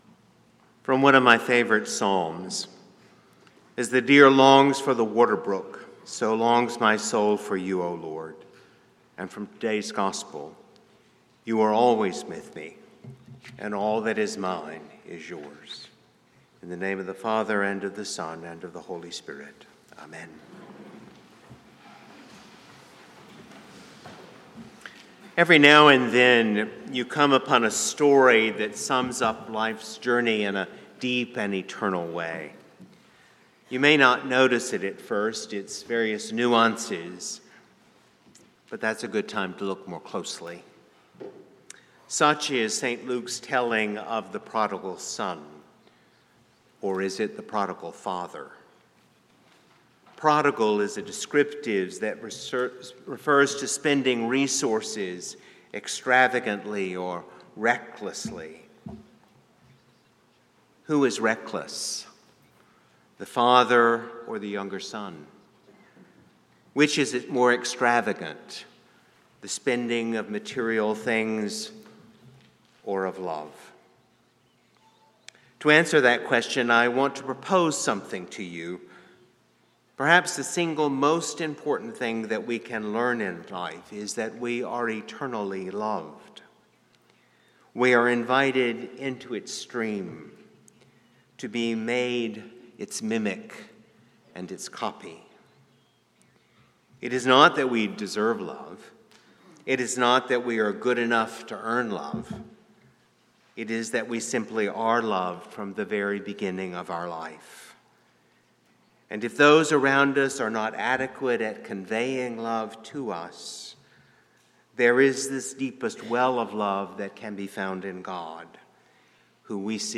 St-Pauls-HEII-9a-Homily-30MAR25.mp3